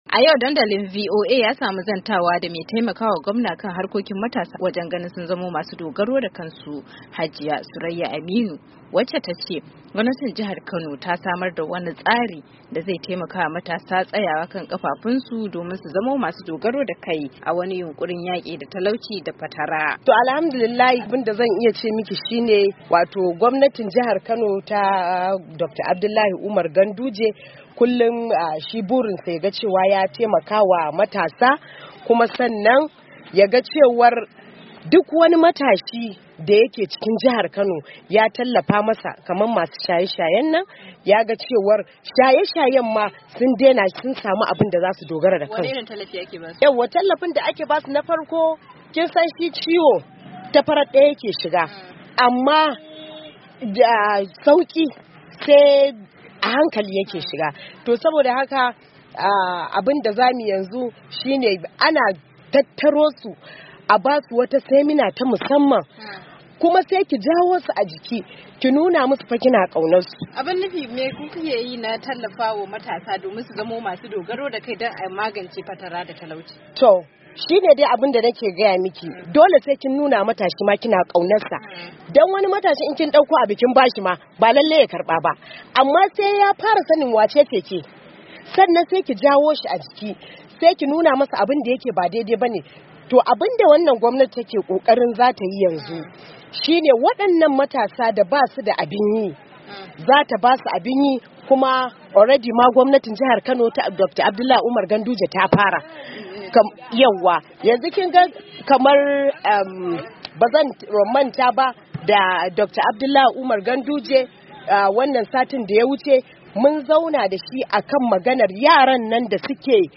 Mai taimakawa Gwamnan jihar Kano, kan harkokin matasa Hajiya Surayya Aminu, ce ta furta haka a lokacin da suka yi wata tattaunawa da wakiliyar Dandalinvoa